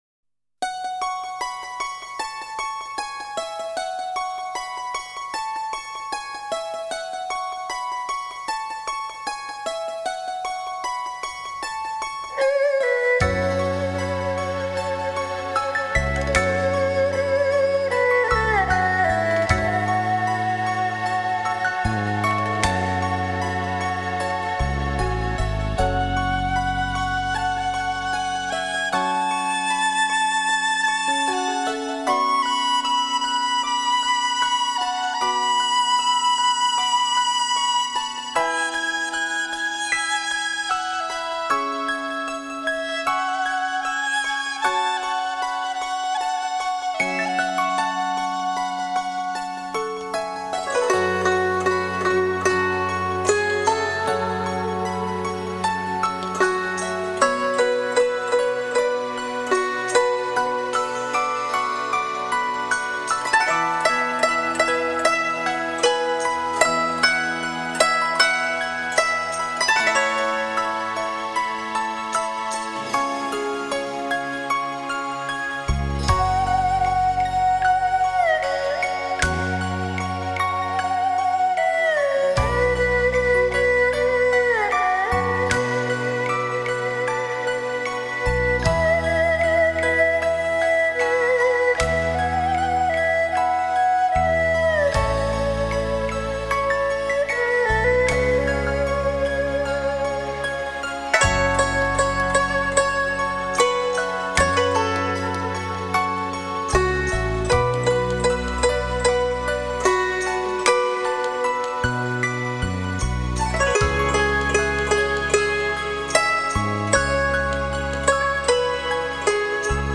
Японская музыка - солнечный день Кото